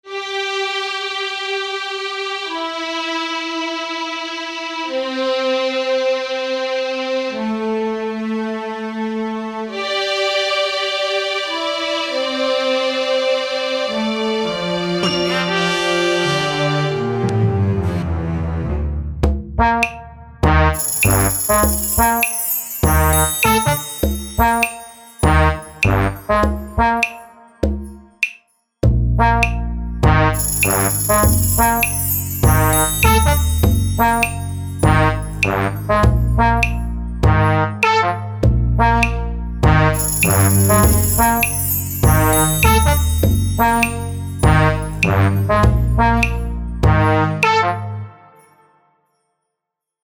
Аудиовизуализация. Утро на волшебной кухне.